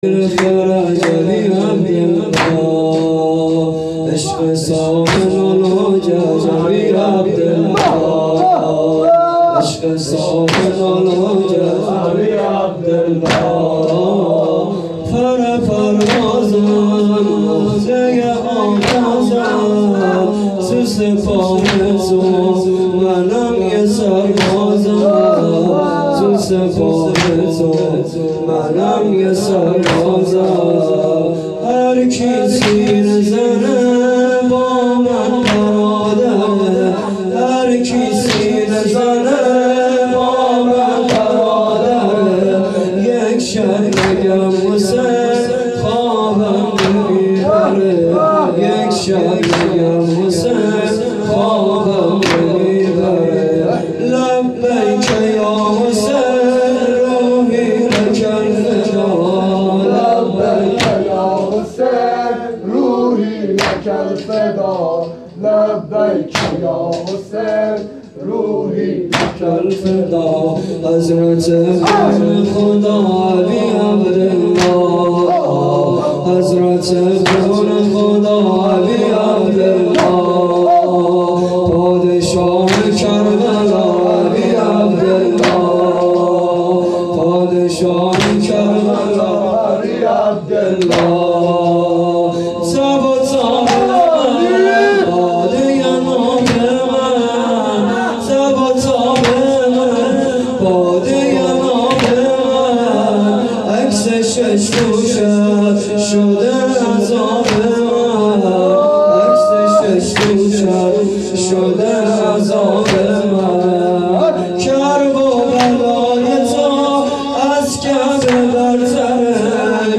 مراسم هفتگی۹۳/۱۱/۱۵